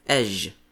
Ezh (Ʒ ʒ) /ˈɛʒ/
En-us-ezh.ogg.mp3